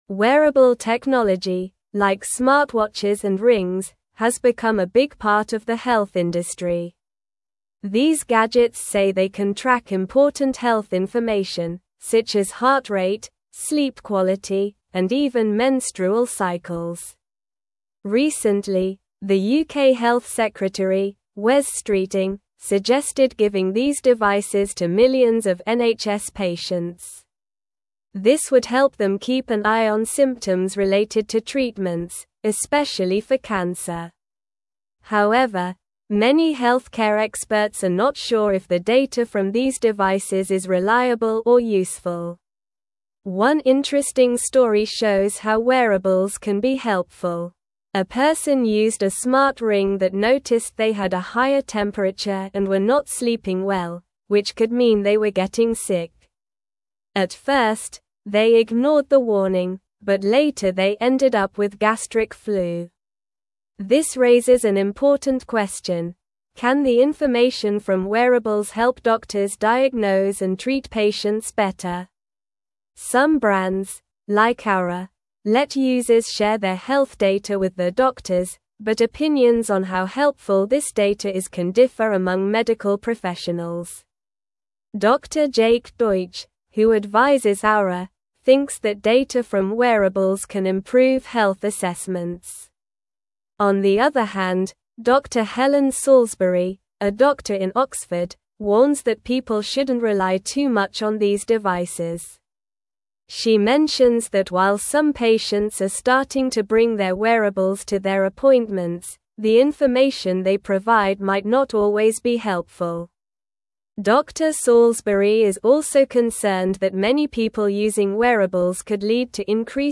Slow
English-Newsroom-Upper-Intermediate-SLOW-Reading-Wearable-Technologys-Impact-on-Healthcare-Benefits-and-Concerns.mp3